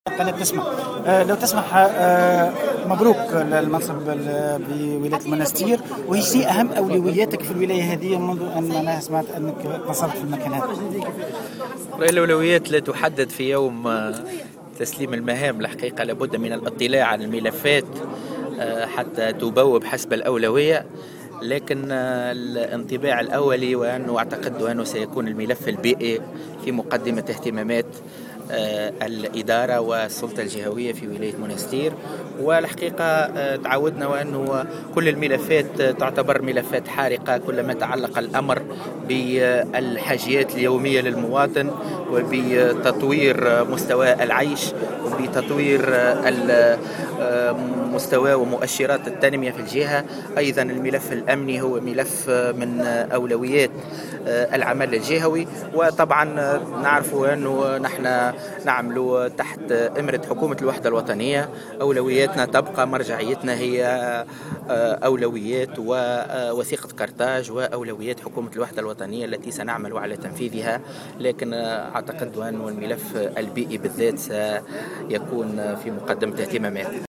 وشدّد السبري، في تصريح لمراسل الجوهرة أف أم، على هامش الحفل الذي أقيم بمقر الولاية، اليوم الثلاثاء، على أن الملف الأمني سينال كذلك الاهتمام اللازم، على أن يتم تحديد بقية الأولويات بعد دراسة الملفات وبالتنسيق مع حكومة الوحدة الوطنية .